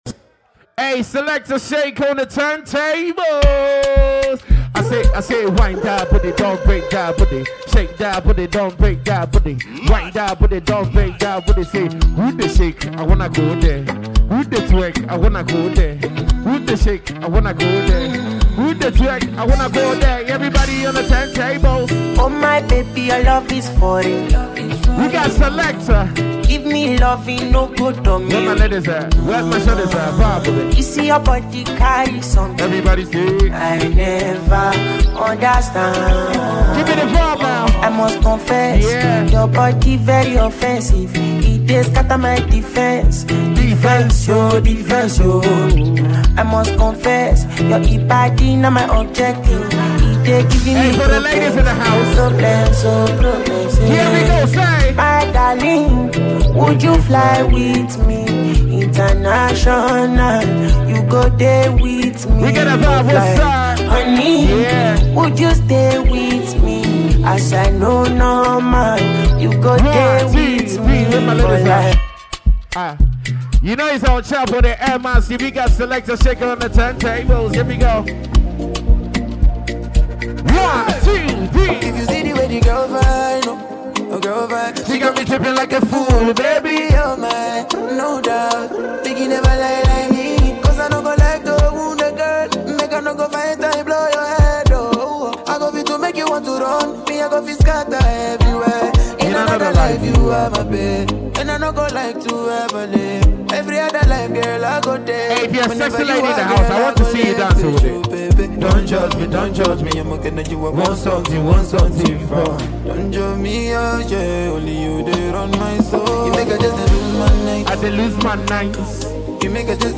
a Ghanaian Disc Jockey